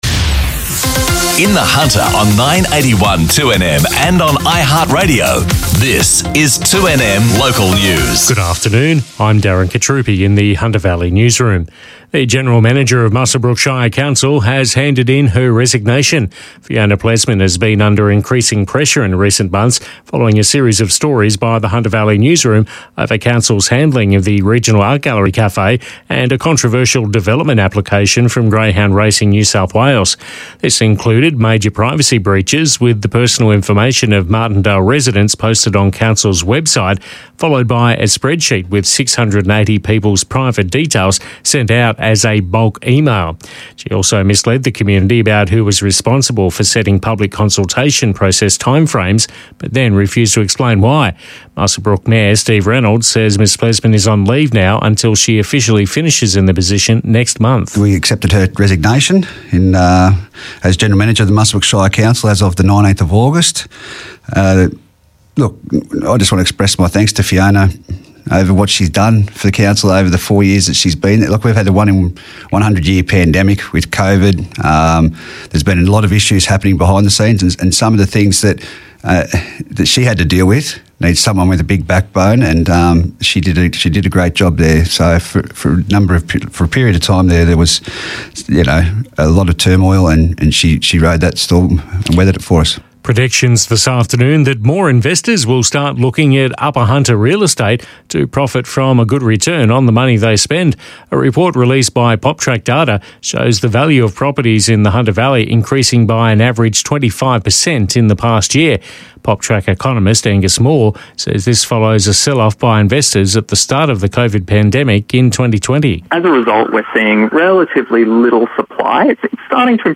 The latest Hunter Valley local news and sport.